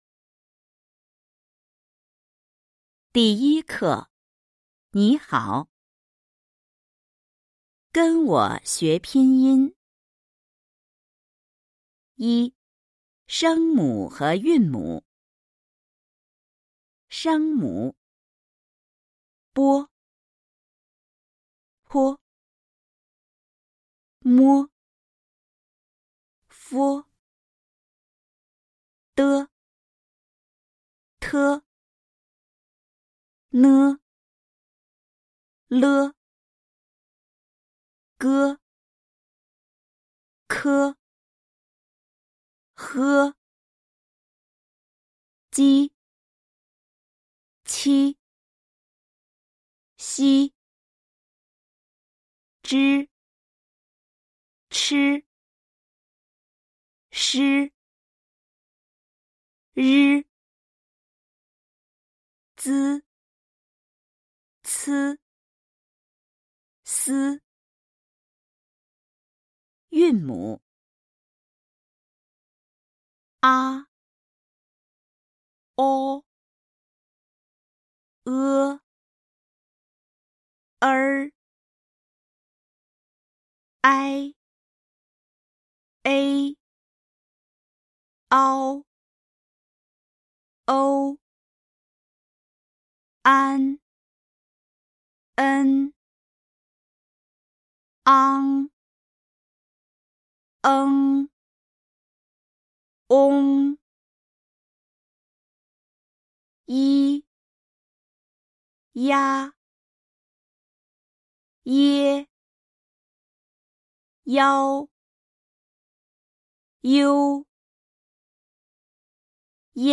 跟我学拼音 Follow Me to Pinyin. Cùng tôi học phiên âm🎧01